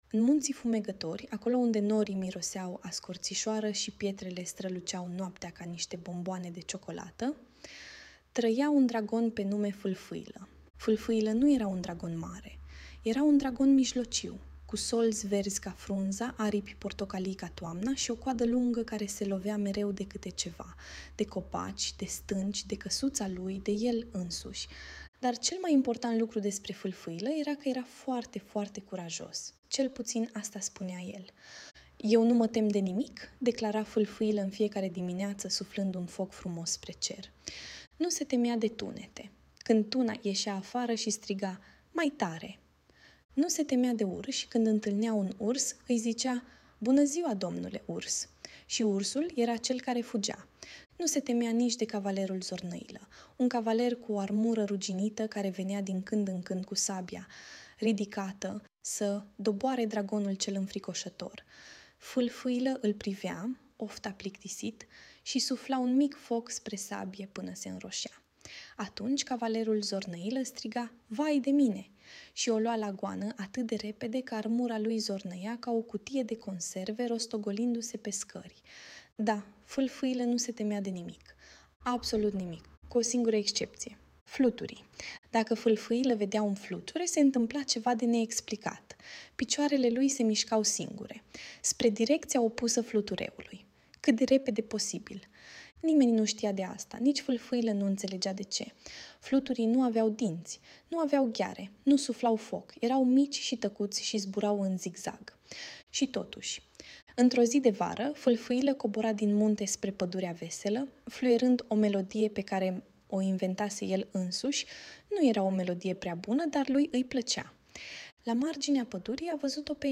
Audiobook Dragonul Falfaila care se temea de fluturi